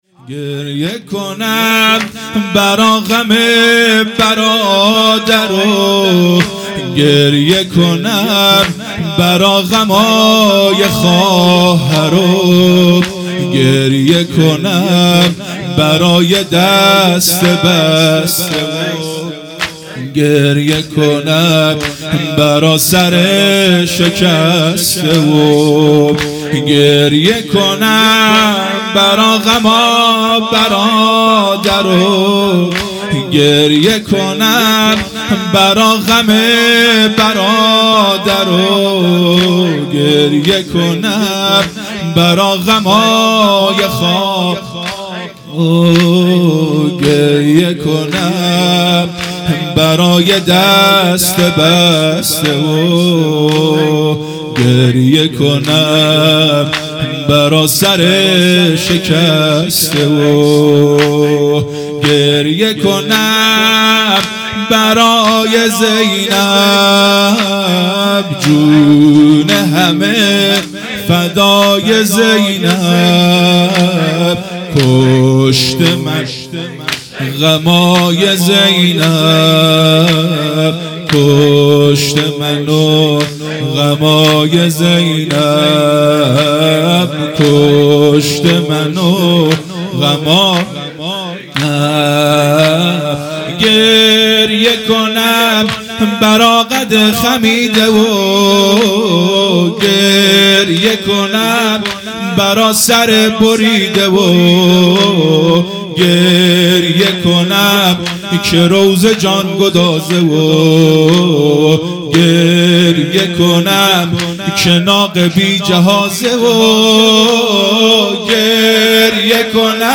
زمینه | گریه کنم برا غم برادر مداح